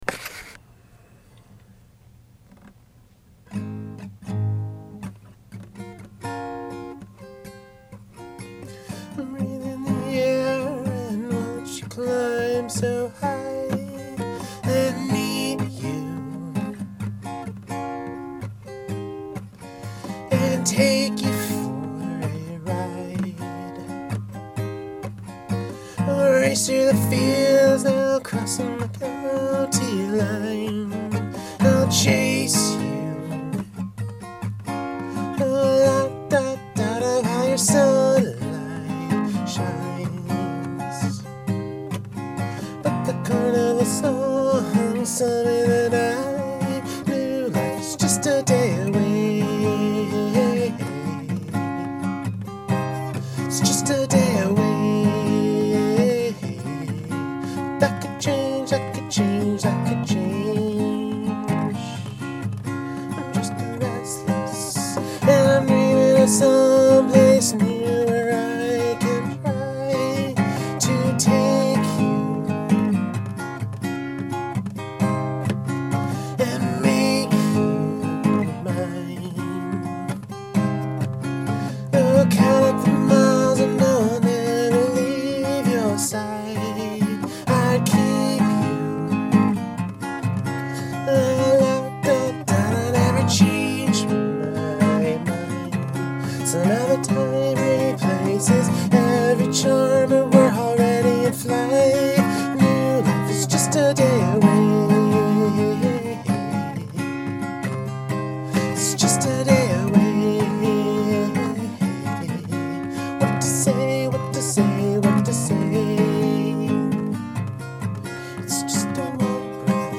Very, very raw.